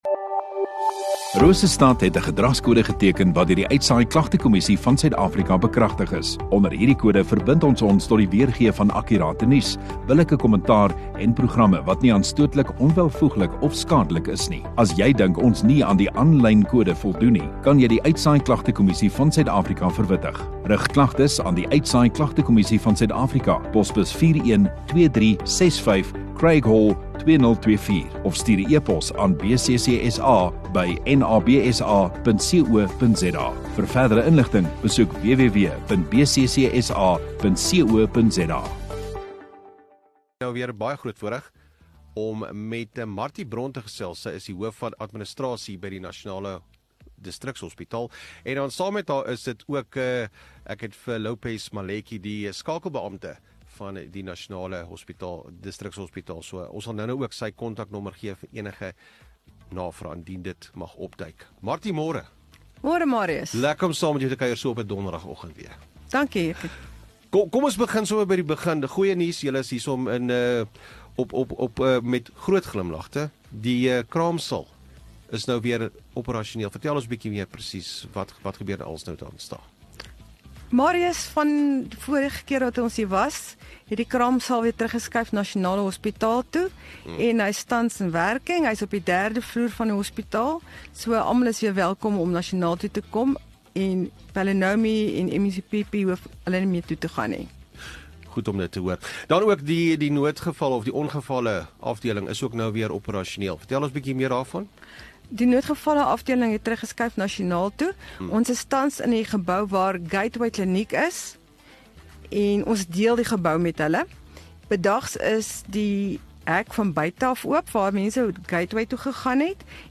View Promo Continue Radio Rosestad Install Gemeenskap Onderhoude 7 Aug Nasionale Distrikshospitaal